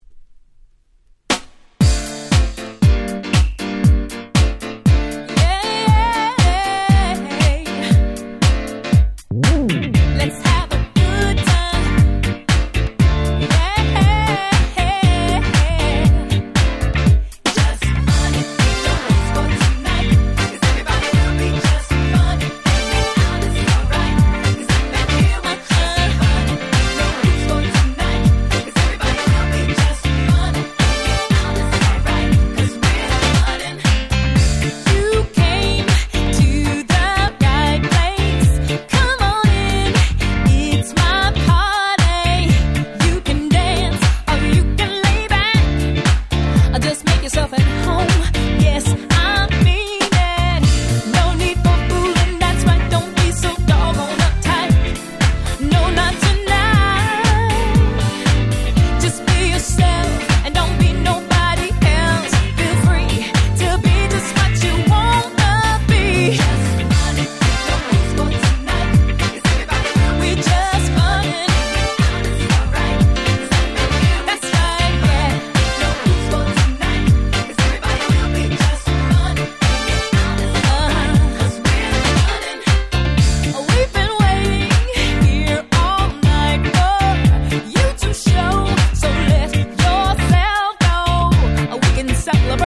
03' Very Nice R&B !!
キャッチーで踊れるアップナンバー！！